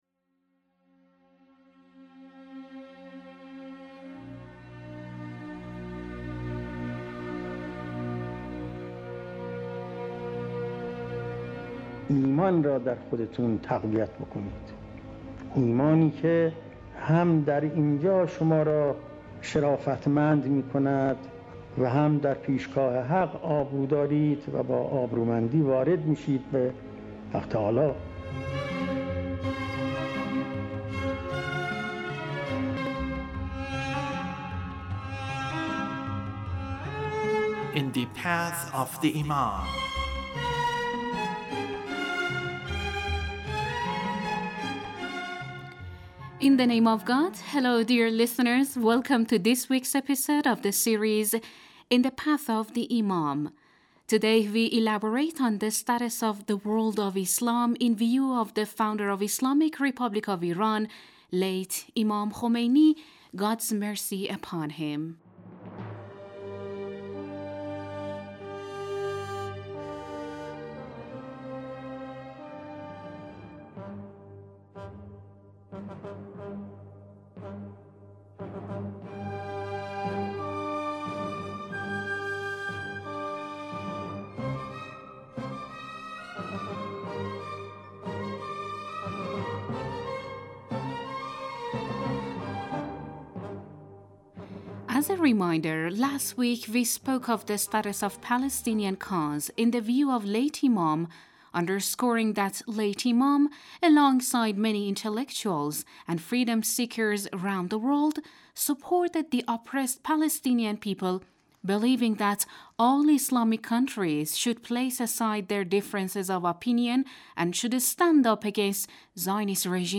The radio series sheds light on the life of the Founder of the Islamic Republic of Iran, Imam Khomeini (God bless his soul) and his struggles against the S...